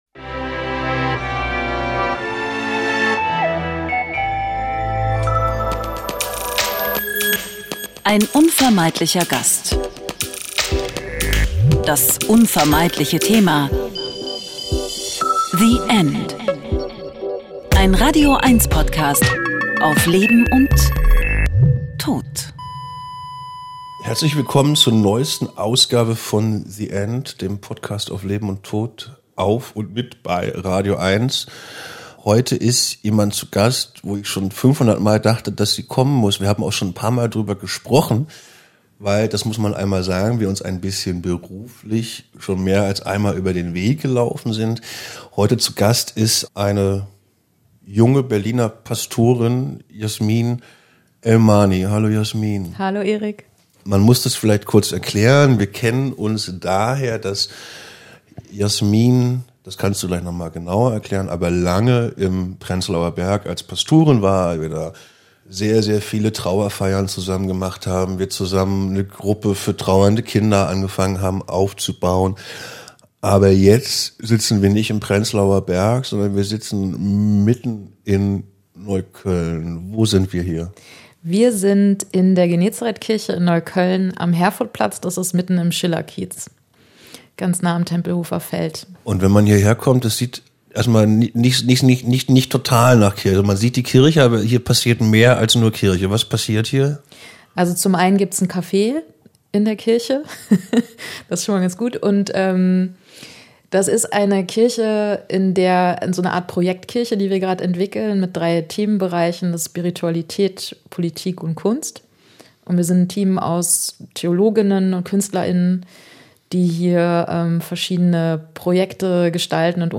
Hilfesuchende können hier Kontakte und Hilfe bekommen. Ein Gespräch über wirklich schwierige Gespräche, Trauern mit und um Kinder und Trost.